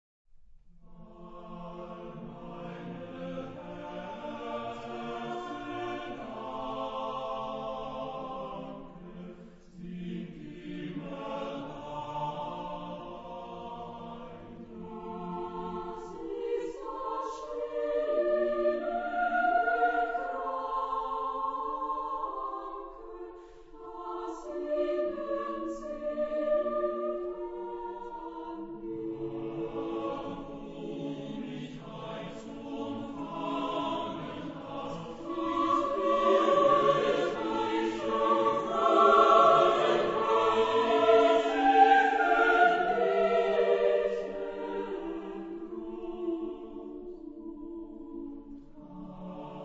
Genre-Style-Form: Secular ; Cycle ; Romantic
Type of Choir: SAATBB  (6 mixed voices )
Tonality: F major